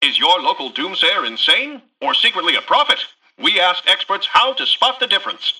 Newscaster_headline_35.mp3